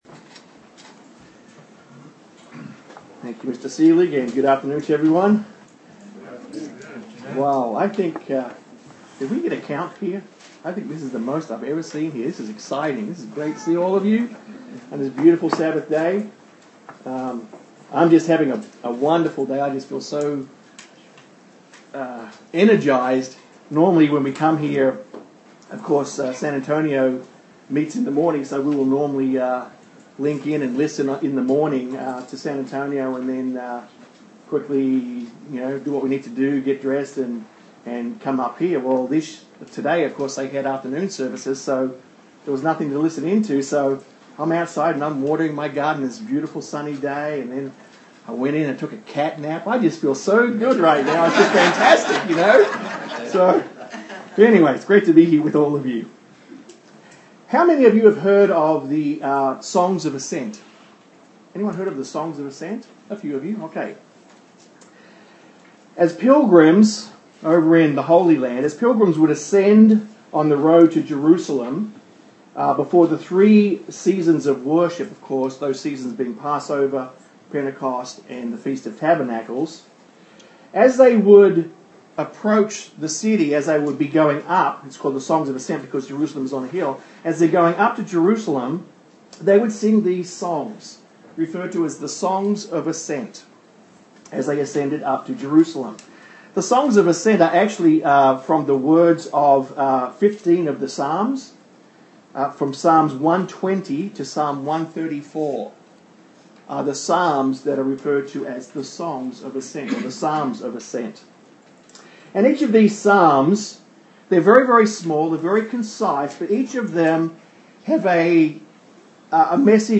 Sermons
Given in Austin, TX